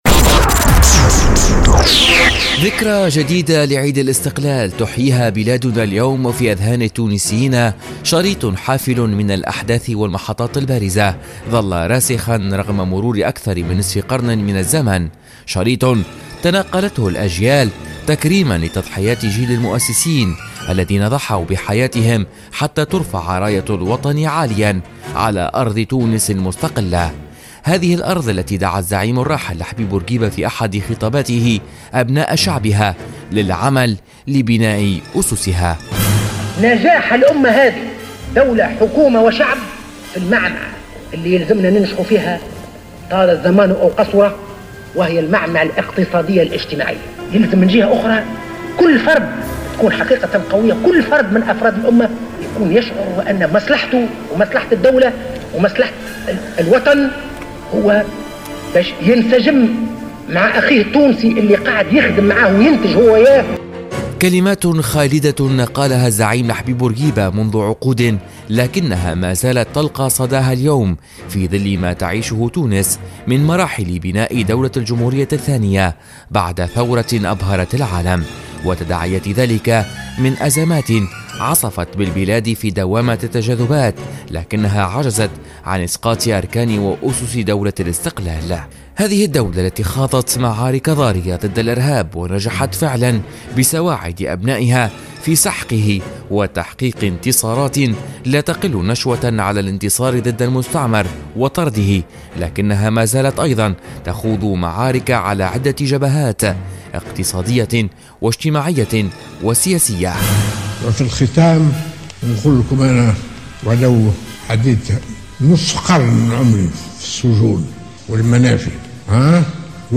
تقرير